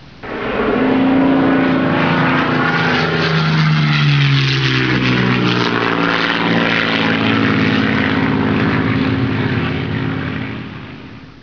Planeflyby
PlaneFlyBy.wav